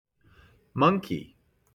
Play Monkey 1 - SoundBoardGuy
Play, download and share Monkey 1 original sound button!!!!
monkey-1.mp3